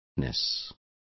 Also find out how cacumen is pronounced correctly.